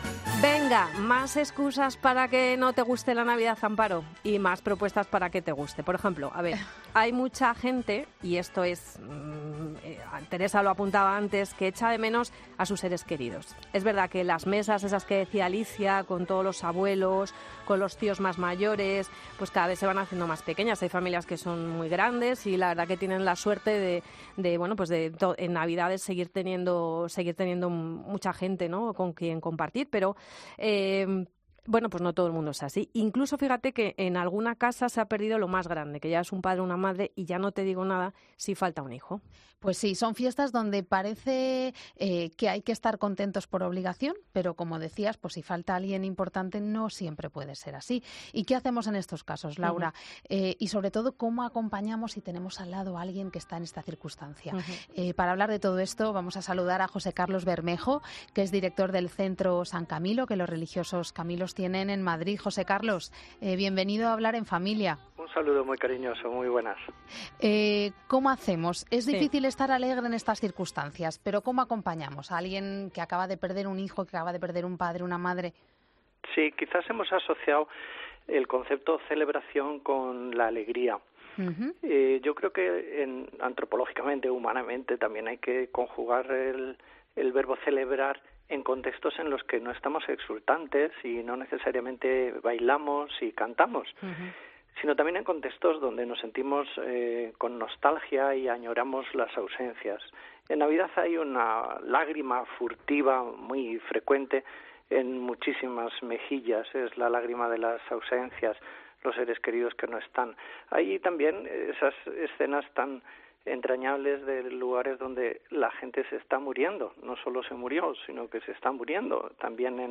Un experto en duelo nos explica cómo llorarles desde el homenaje y desde el convencimiento que ahora están en algún lugar mejor